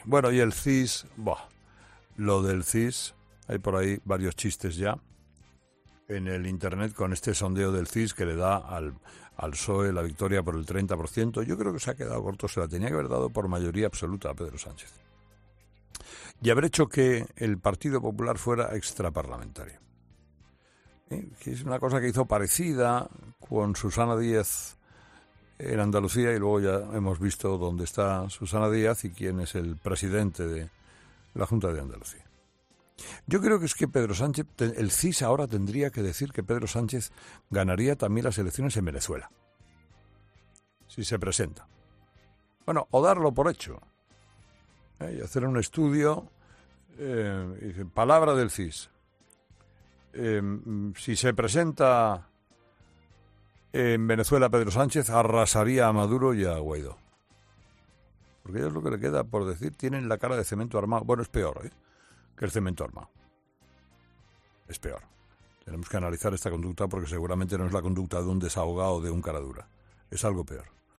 La risotada de Herrera en directo a cuenta del último CIS de Tezanos